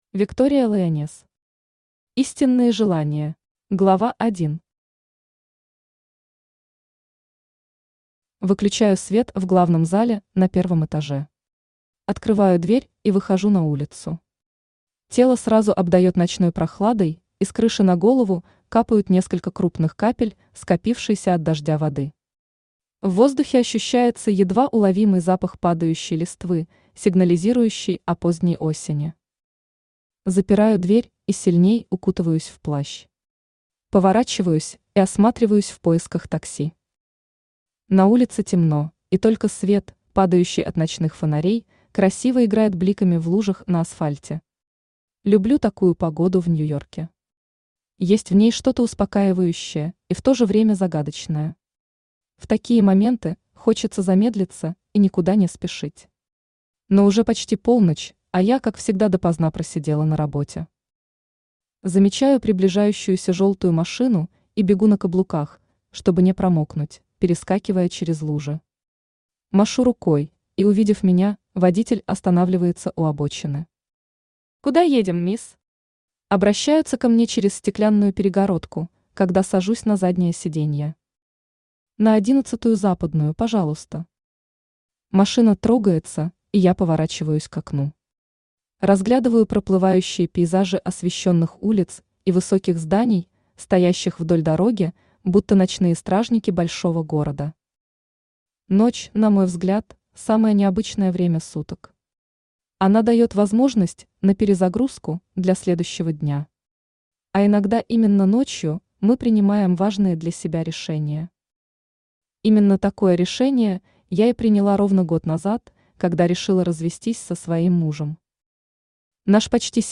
Аудиокнига Истинные желания | Библиотека аудиокниг
Aудиокнига Истинные желания Автор Виктория Лайонесс Читает аудиокнигу Авточтец ЛитРес.